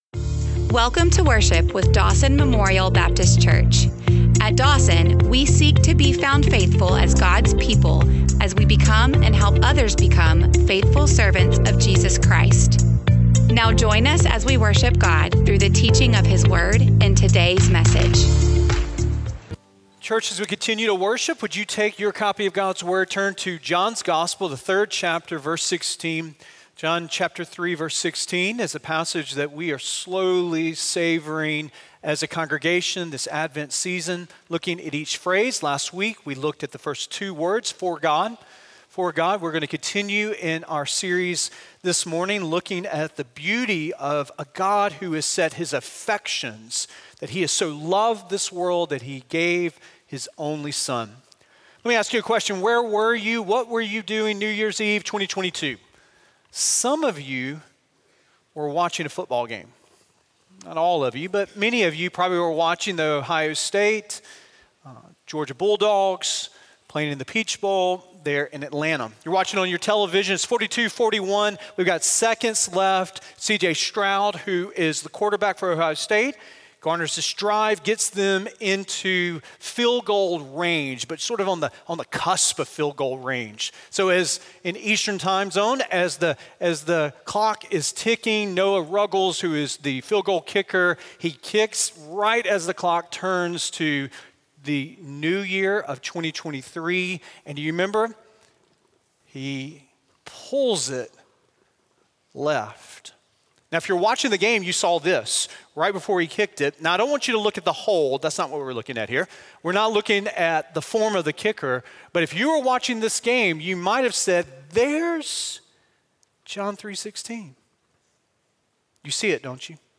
128sermonaudio.mp3